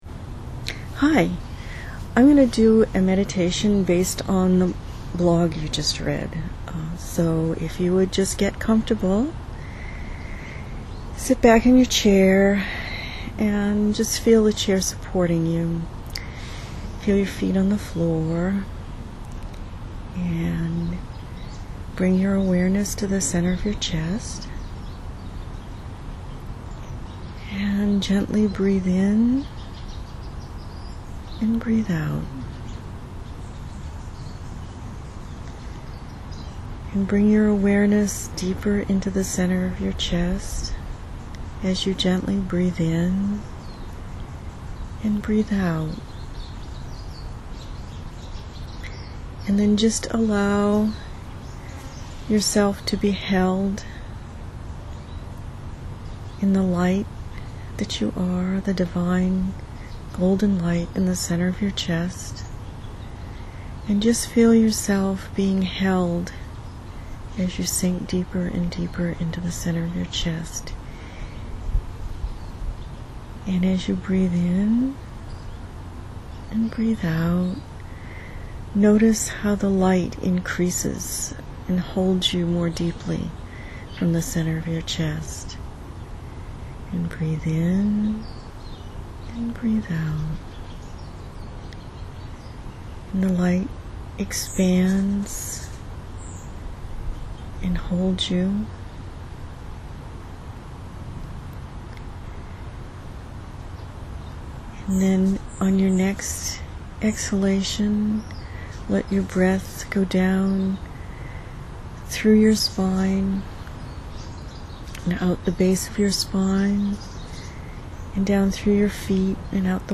I did a brief meditation based on their words.